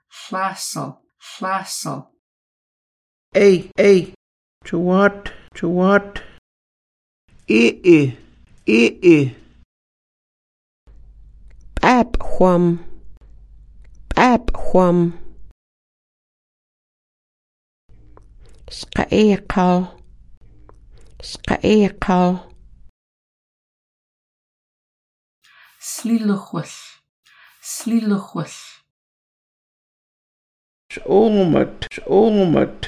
Listen to the elder
Audio Vocabulary and Phrases